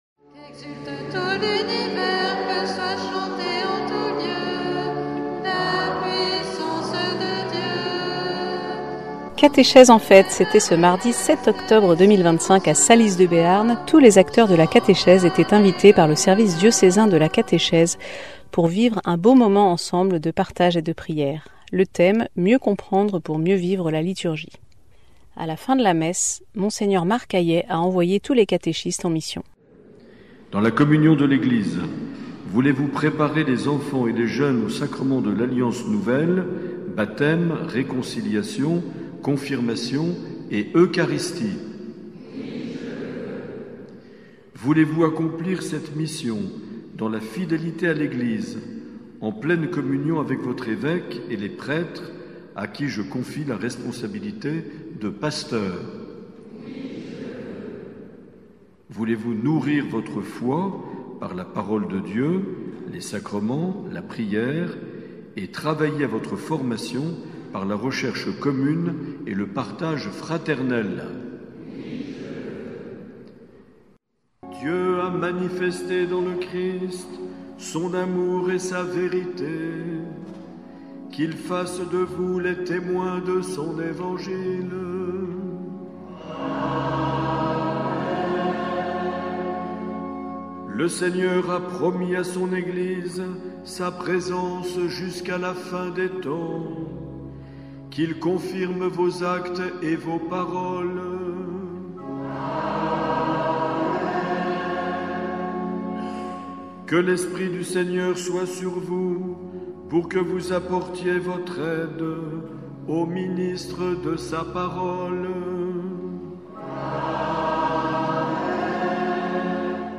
La journée Catéchèse en fête a eu lieu à Salies-de-Béarn à l'invitation du Service de la catéchèse du diocèse. Reportage.